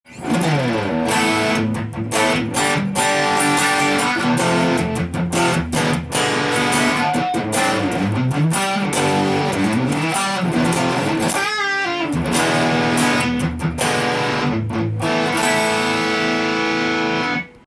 試しに弾いてみました
リアのハムバッカーで弾いてみましたが低音から高音まで出る
綺麗な音域にビックリしました。
に昔はありましたが、このギターは鳴ります！良いですね！